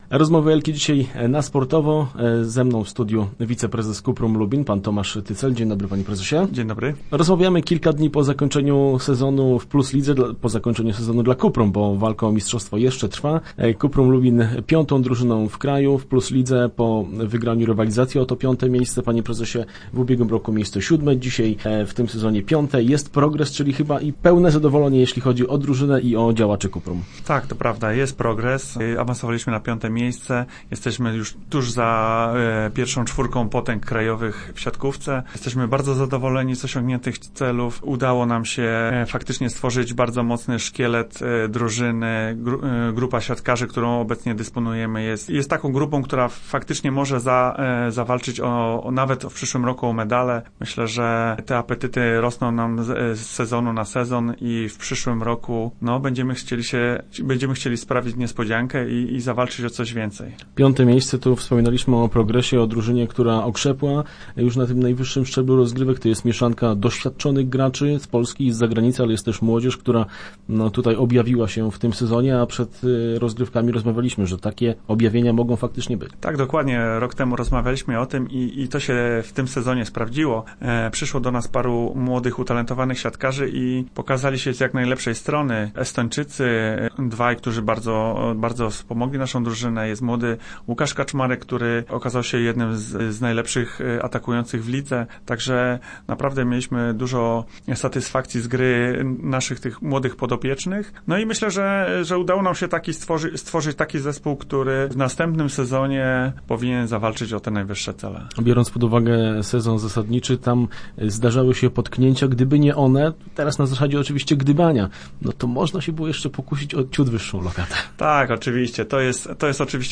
Start arrow Rozmowy Elki arrow Rośnie apetyt na medal